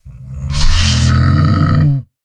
Minecraft Version Minecraft Version snapshot Latest Release | Latest Snapshot snapshot / assets / minecraft / sounds / mob / camel / stand2.ogg Compare With Compare With Latest Release | Latest Snapshot